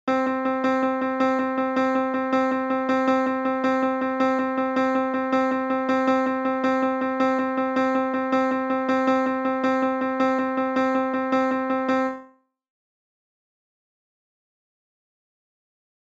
Note the just one pitch used in the example.
Here's a 'brighter tempo' version of the last idea.